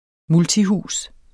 Udtale [ ˈmulti- ]